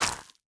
Minecraft Version Minecraft Version 1.21.5 Latest Release | Latest Snapshot 1.21.5 / assets / minecraft / sounds / block / roots / break2.ogg Compare With Compare With Latest Release | Latest Snapshot
break2.ogg